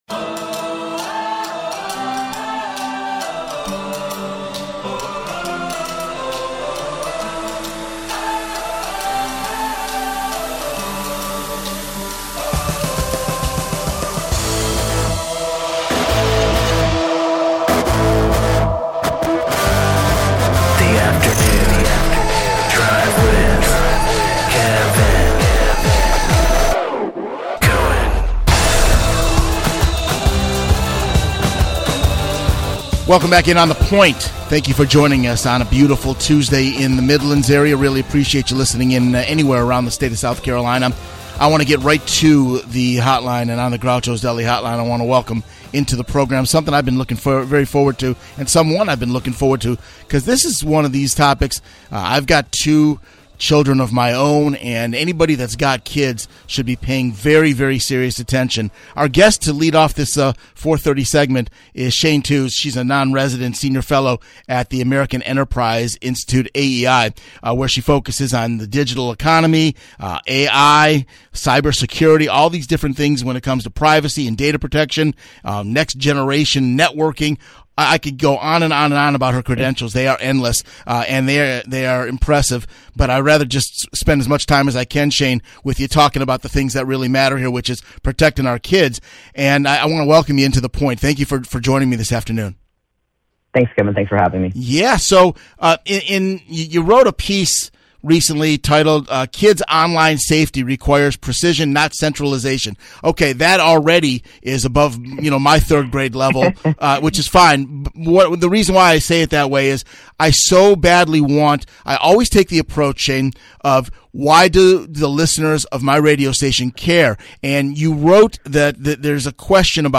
Here is that conversation.